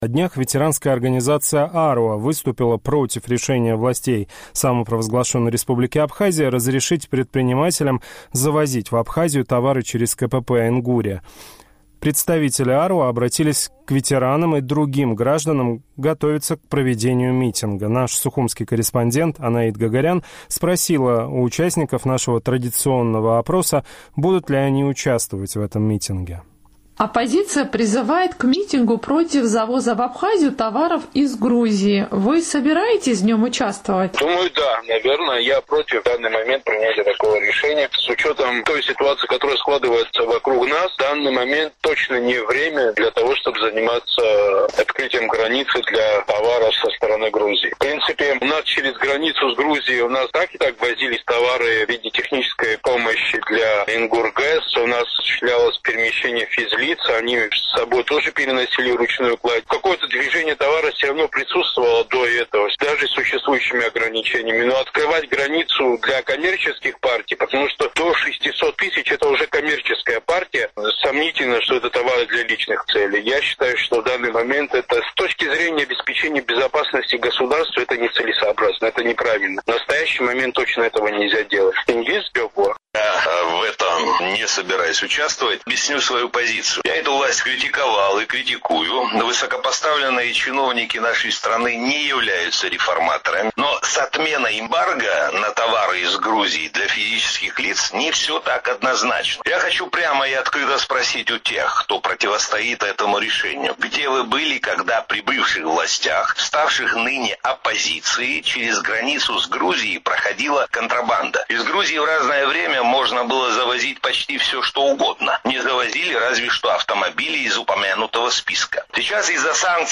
Сухумский опрос – о призыве оппозиции к митингу